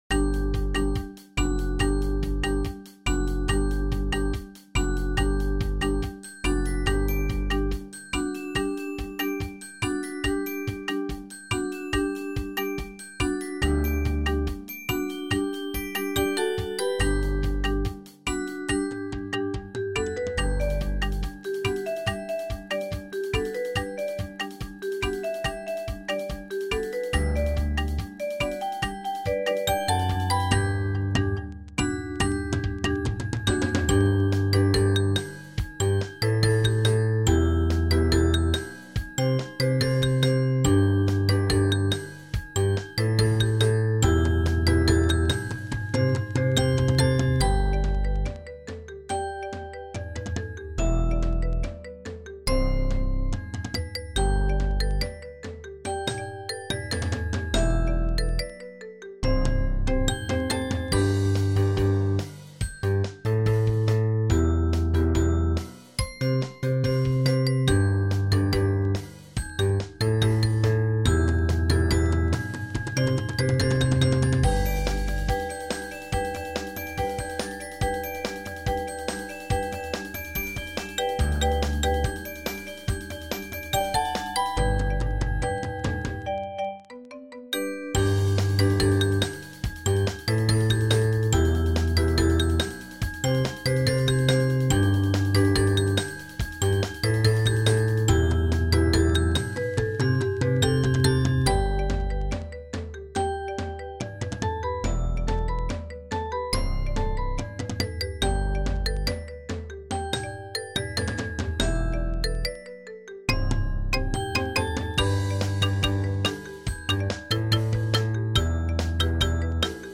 Rubriek: Mallet-Steelband Muziek